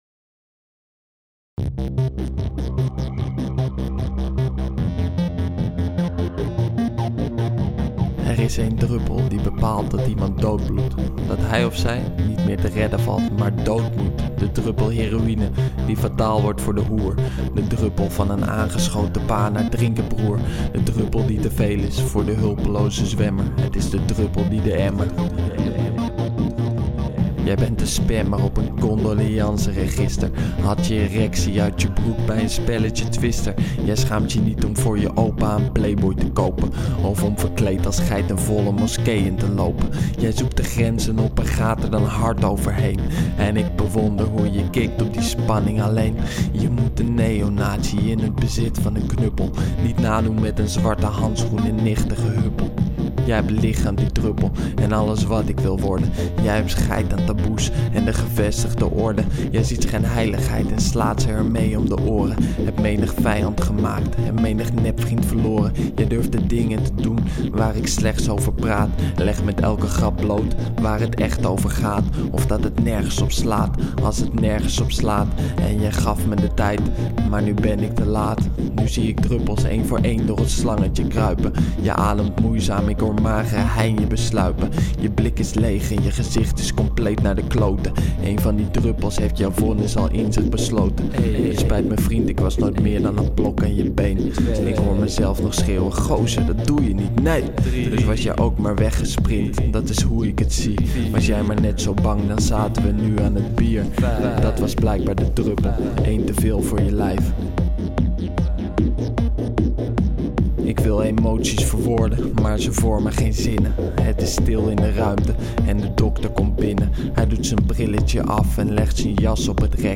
De druppel (house)